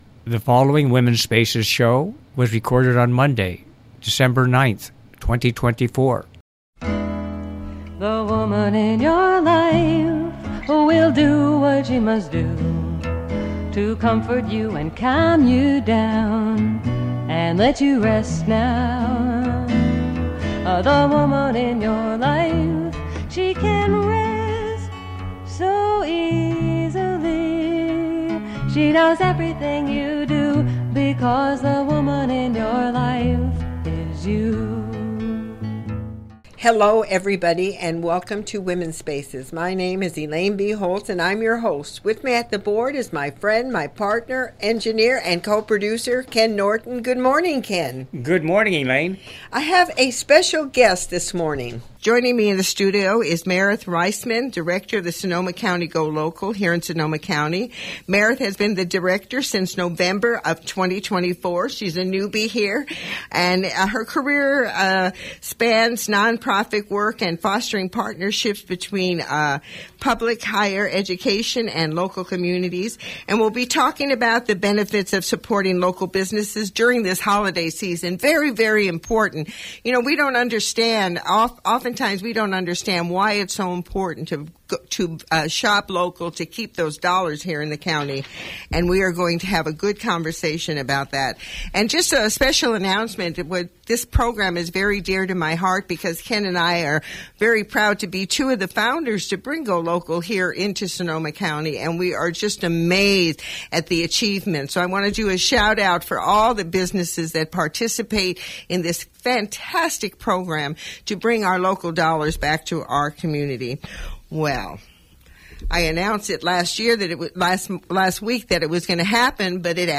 I have a special guest this morning.